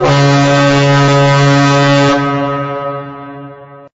hornNearSingle.ogg